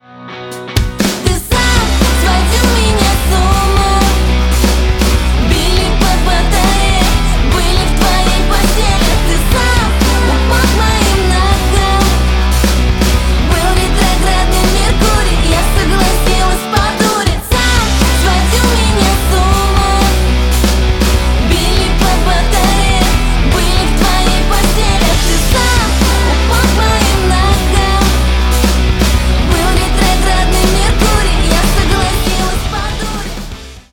Русские Рингтоны » # Рок Металл Рингтоны